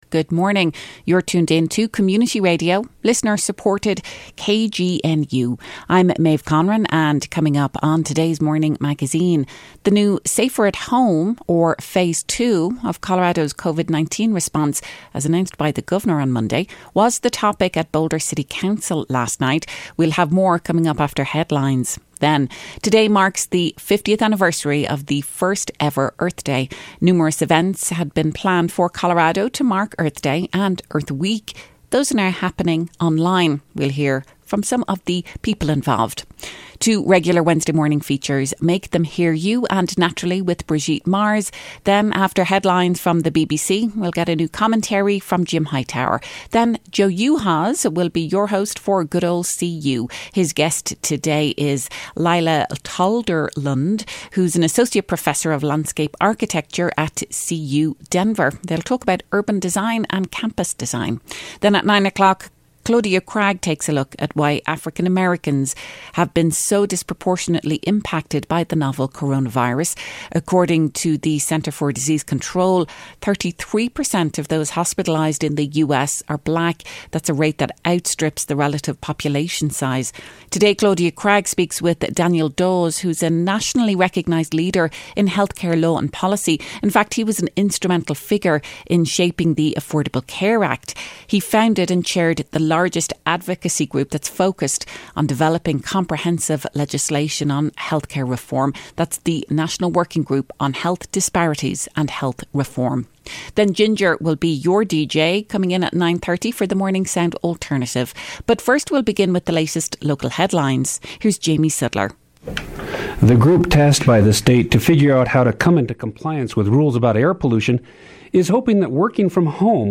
We hear how Boulder City Council is responding to the announcement of “safer-at-home” or phrase two of Colorado’s COVID-19 response followed by an interview with local environmental activists about their online Earth Day events.